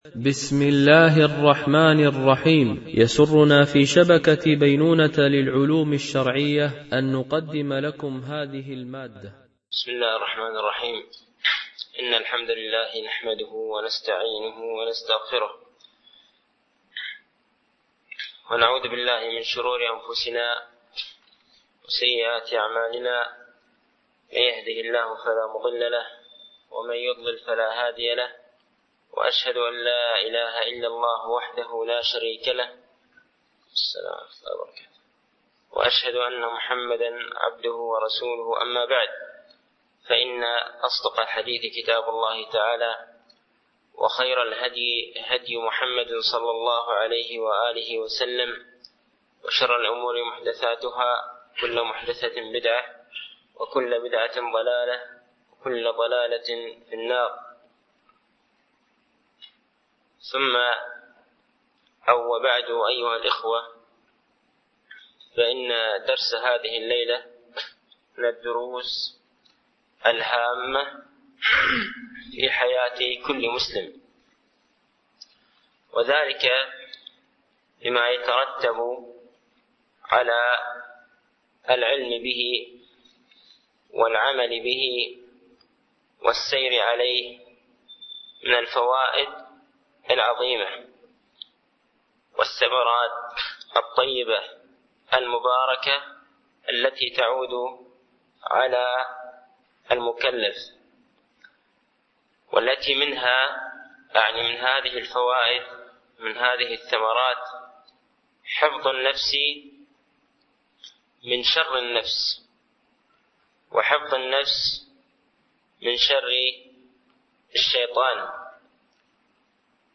شرح أعلام السنة المنشورة ـ الدرس 121 ( ما هو الصراط المستقيم الذي أمرنا الله تعالى بسلوكه ، ونهانا عن اتباع غيره ؟ )